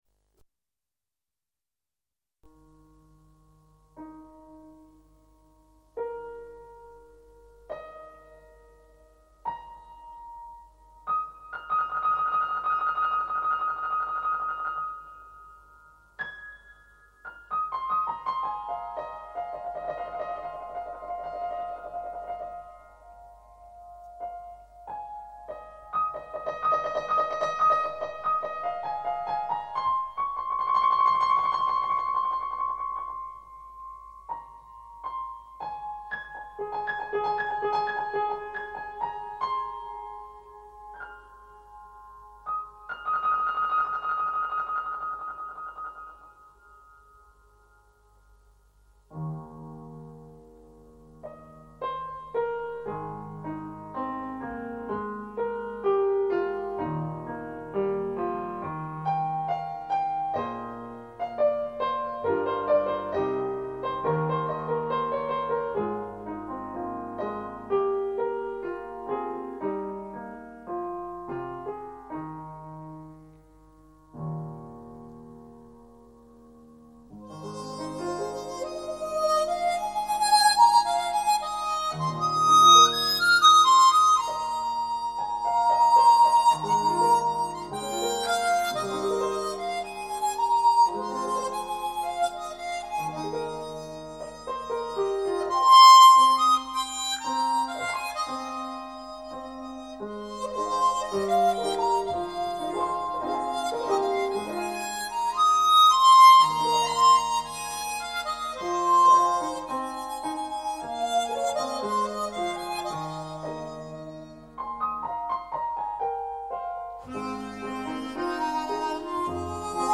音乐里到处都是叹息的声音。
当音乐向低处哀回过渡的时候，鼻尖里有酸涩的味道，绝望使这个故事如此深奥。
再起的音乐，哀而不哭，哭也无声，覆盖在一切起承转合之上的悲郁的调子，使这个故事的一举一动都有了份量。
一直以来对古典的纯音乐情有独钟。